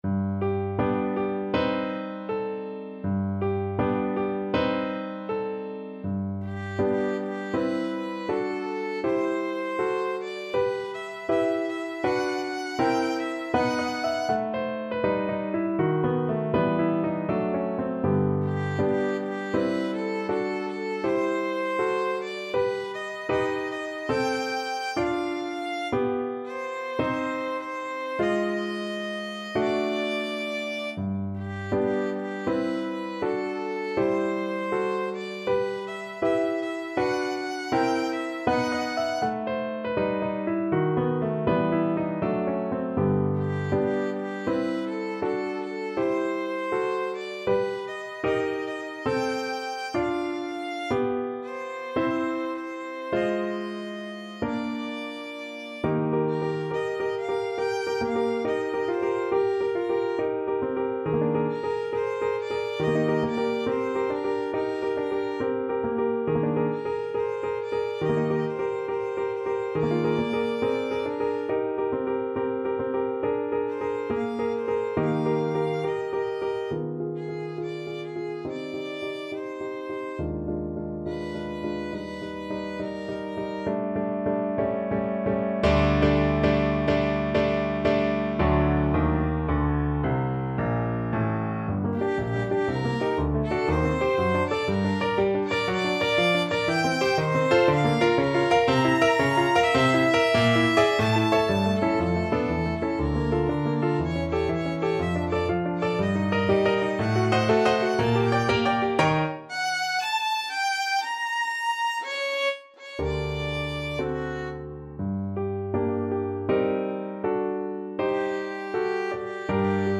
2/4 (View more 2/4 Music)
Moderato =80
Classical (View more Classical Violin Music)